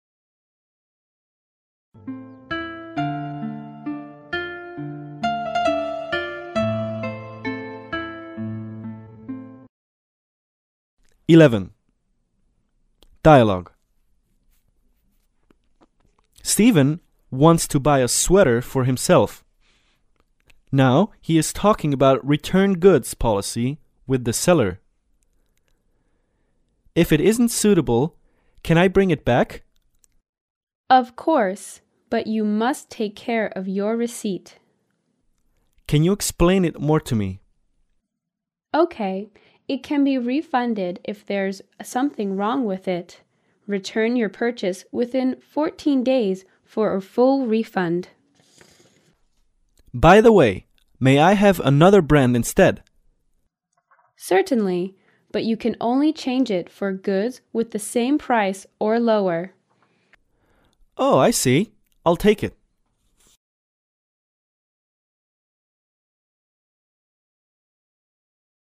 万用英语口语情景对话
对话